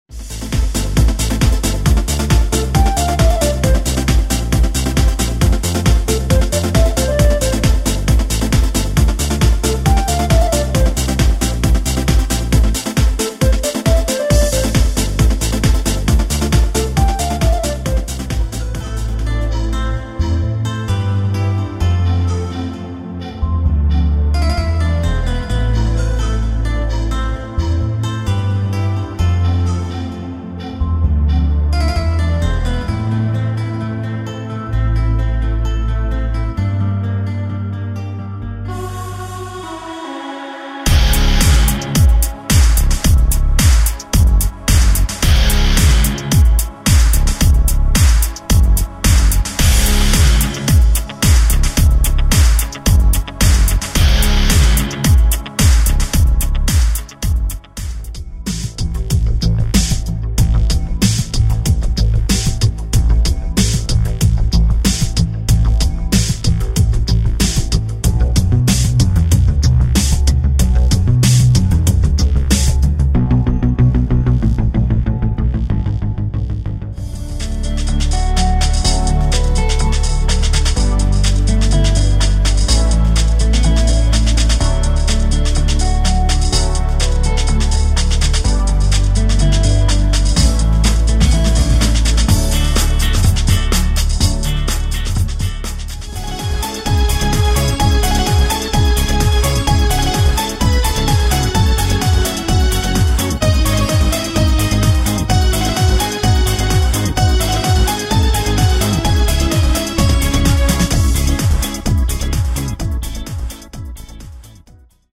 Демо микс написанных мною аранжировок